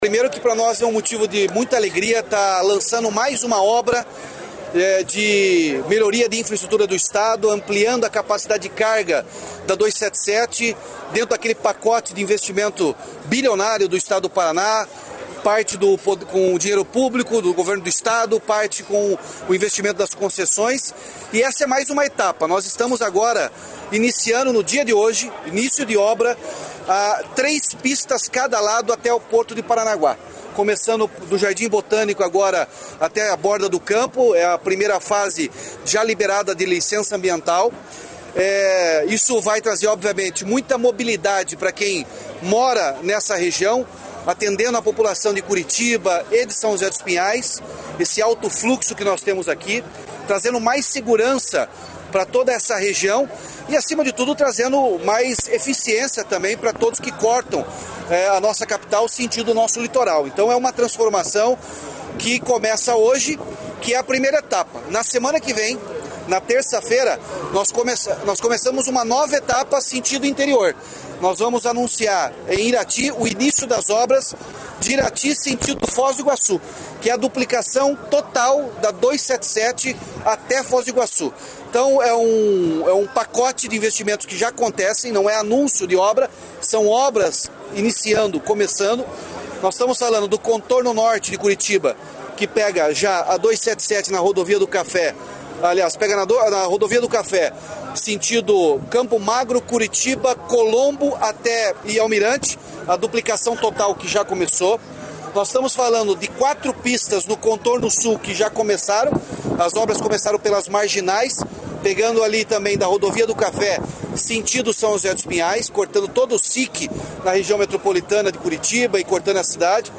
Sonora do governador Ratinho Junior sobre as obras de novas faixas na BR-277, entre Curitiba e São José dos Pinhais